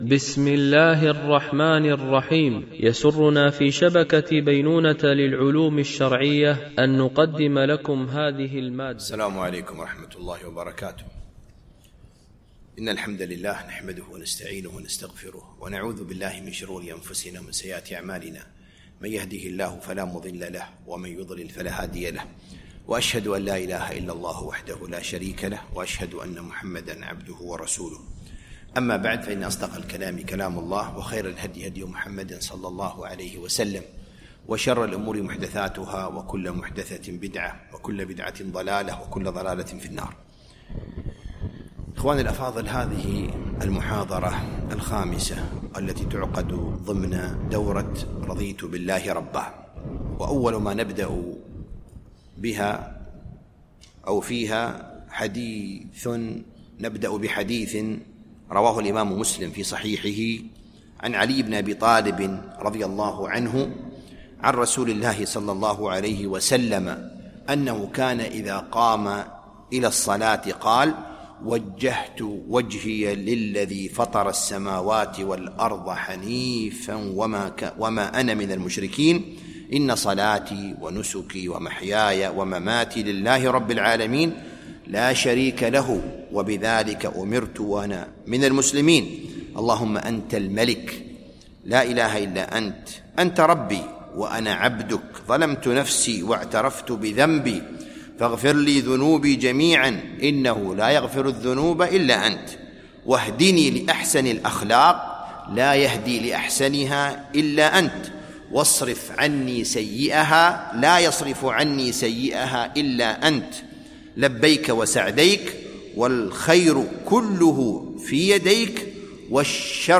دورة علمية بعنوان: رضيت بالله ربا
مسجد الورقاء الكبير - دبي